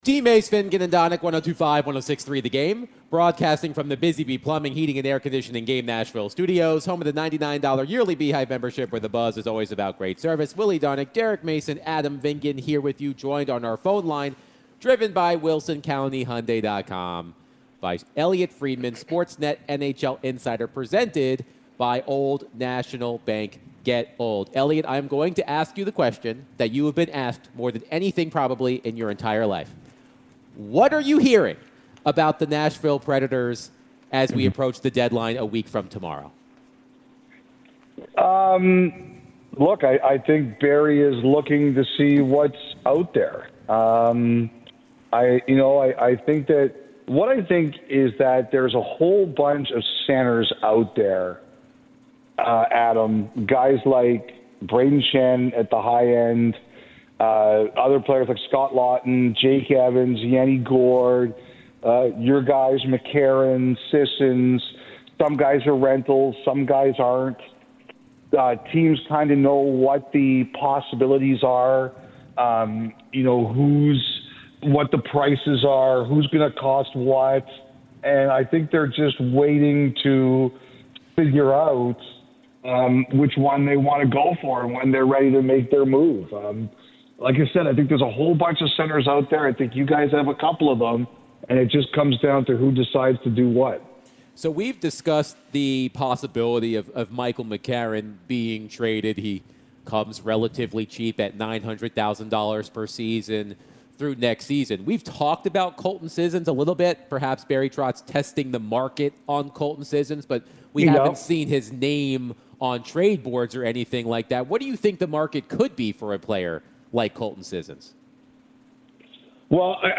NHL Insider Elliotte Friedman joined DVD to discuss the Nashville Predators, the NHL before the trade deadline, and more